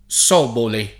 vai all'elenco alfabetico delle voci ingrandisci il carattere 100% rimpicciolisci il carattere stampa invia tramite posta elettronica codividi su Facebook sobole [ S0 bole ] s. f. — raro latinismo lett. per «prole, discendenza» (senza plurale)